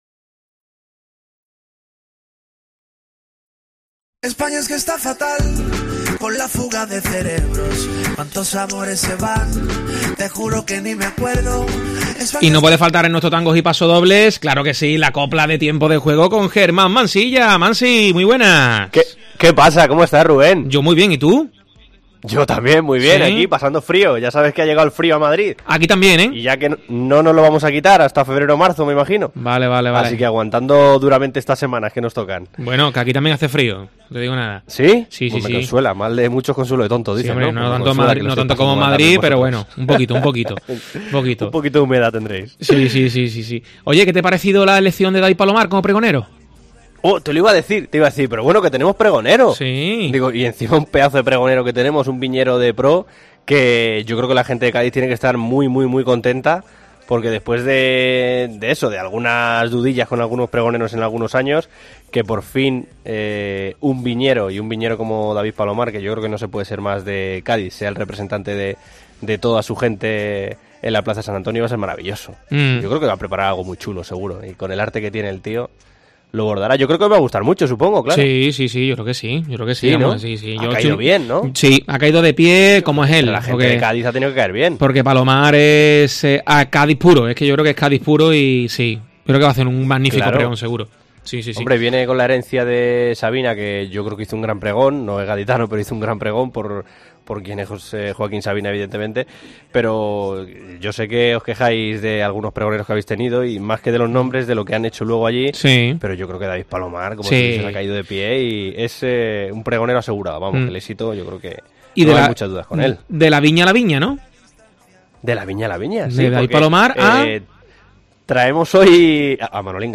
Mostradores de la Viña en la copla de Tiempo de Juego
Carnaval